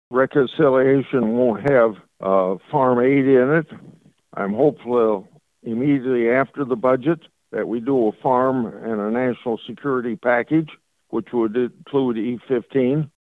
Senator Grassley made his comments on Tuesday during his weekly ag conference call with farm broadcasters and reporters.